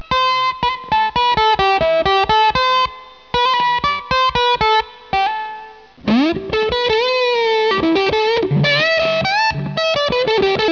octavef.wav